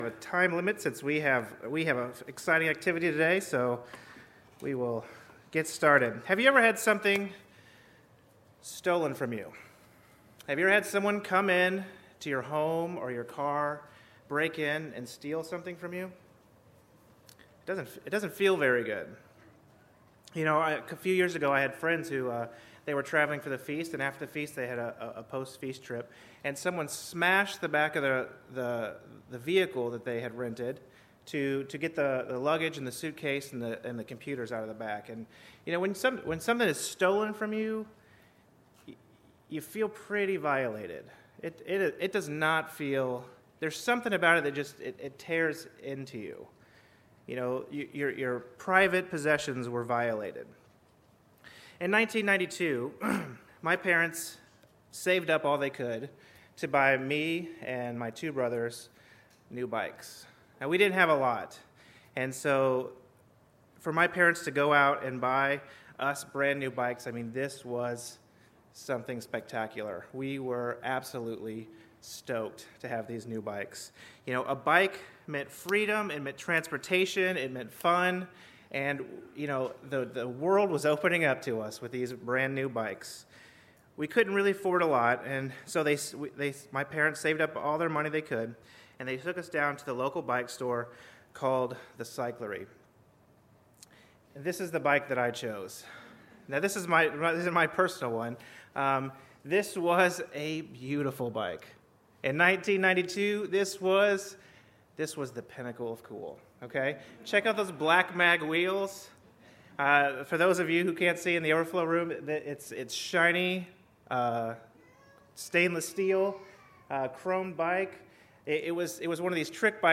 This sermon was given at the Lake George, New York 2018 Feast site.